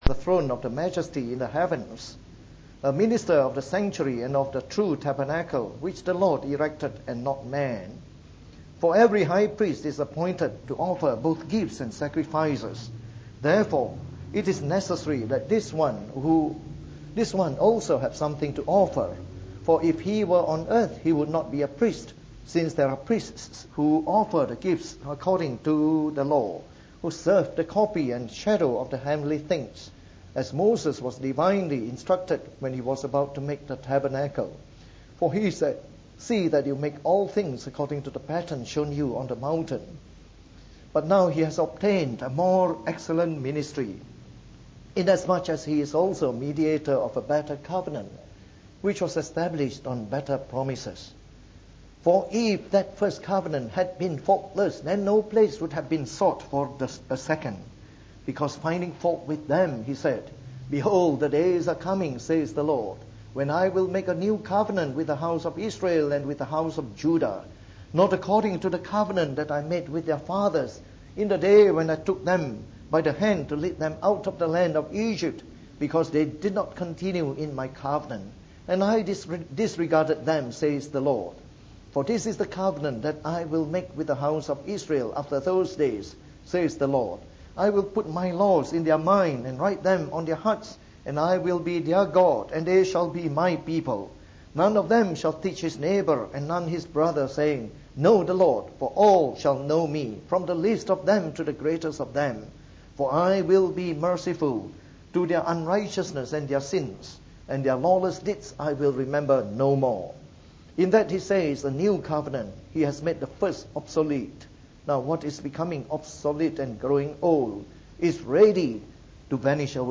From our series on the “Epistle to the Hebrews” delivered in the Evening Service.